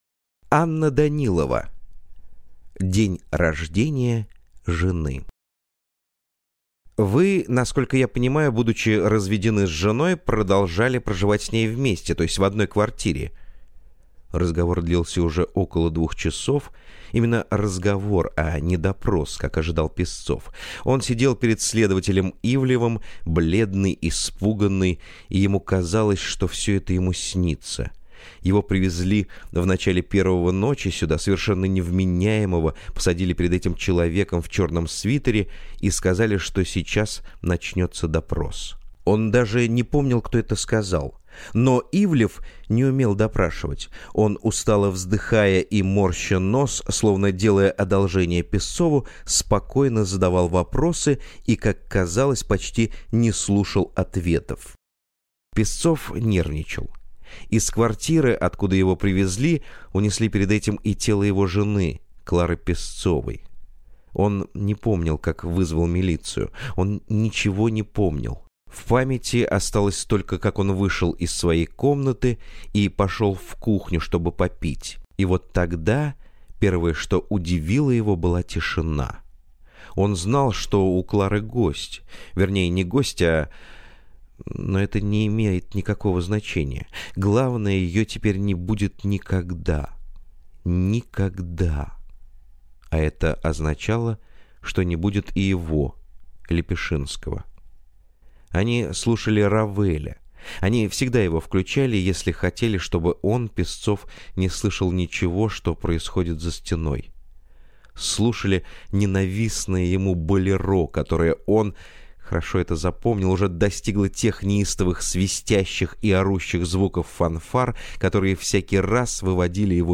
Aудиокнига День рождения жены